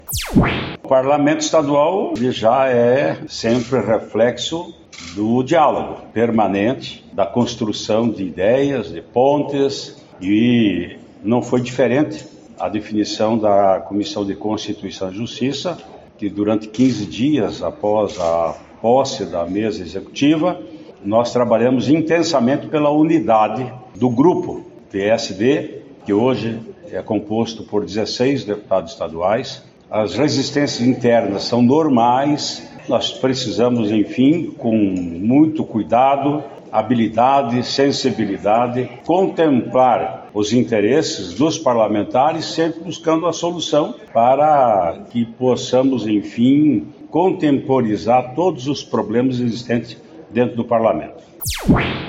Em coletiva, Traiano fala sobre importância diária do diálogo no Parlamento.
(Sonora)